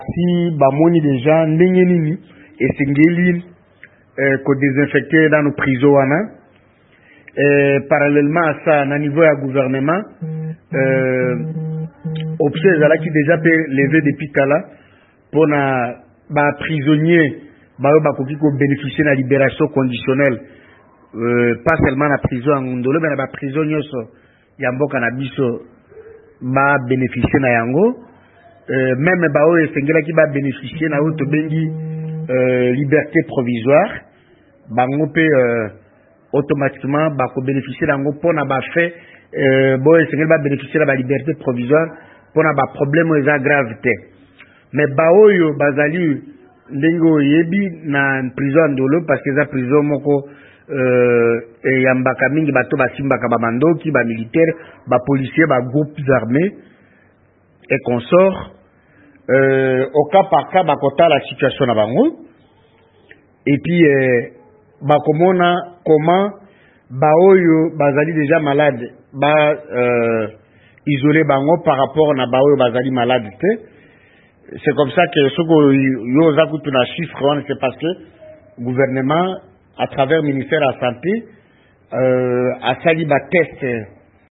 RDC ekomi na bato 682 na COVID19 VOA Lingala ebengaki ministre ya makoki ma bomoto, André lite Asebe.